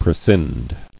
[pre·scind]